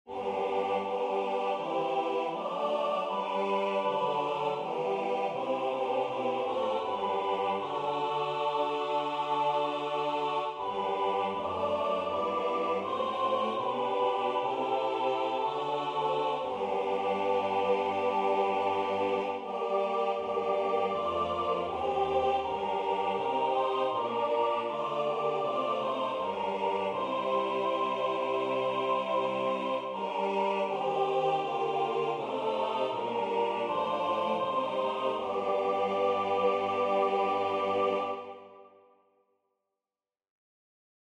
4-part Negative Harmony Example 1 – Aus Meines Herzens Grunde.
Audio with Dorian Plagal HC, and Major I ending)